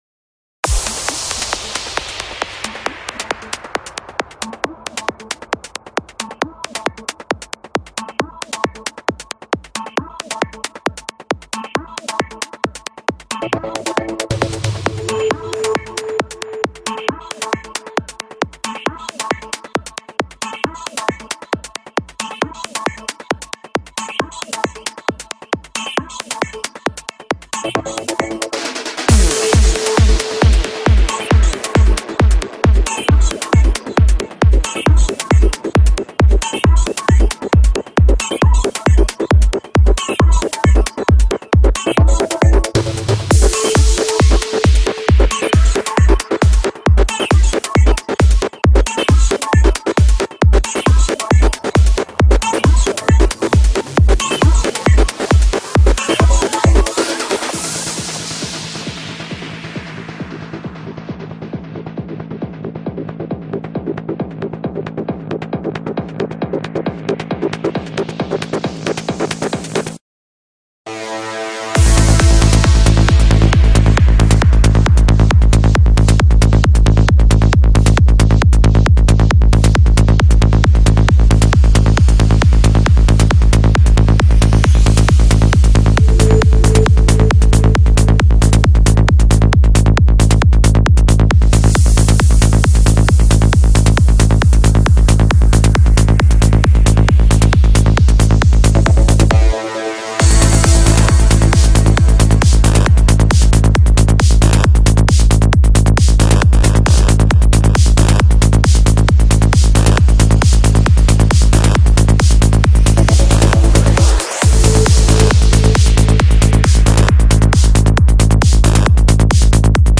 música psicodélica y trance progresivo